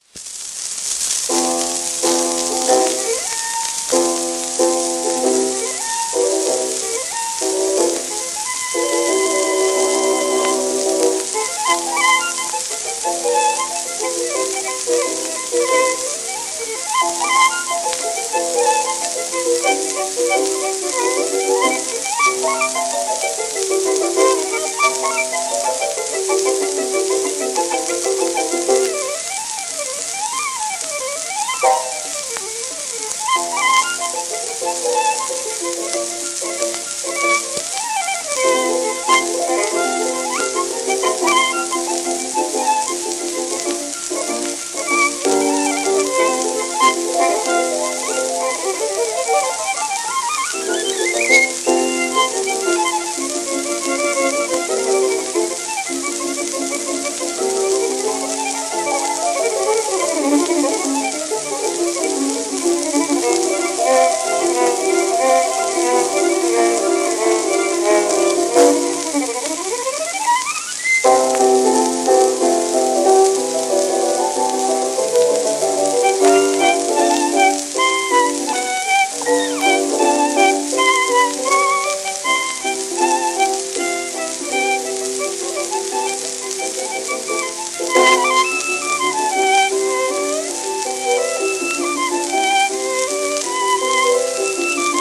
w/ピアノ
12インチ片面盤
旧 旧吹込みの略、電気録音以前の機械式録音盤（ラッパ吹込み）